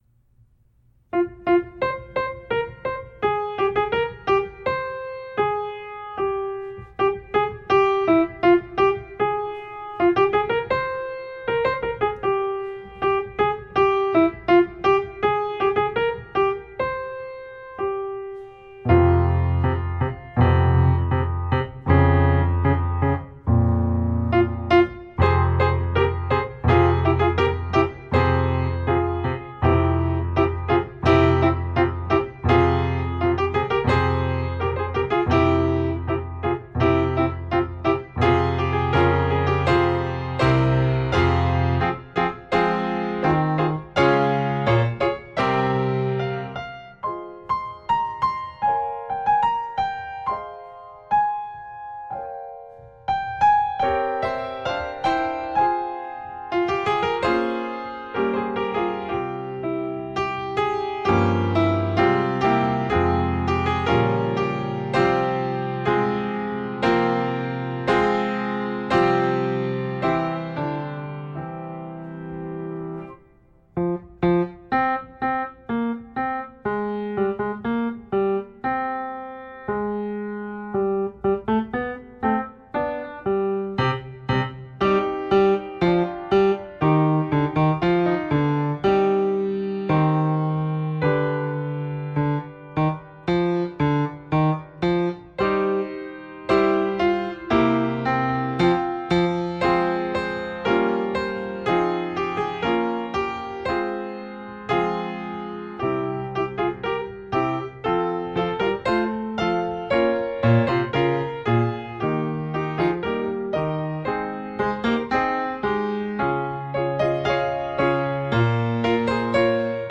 Piano Improvisations
Christmas Improvisations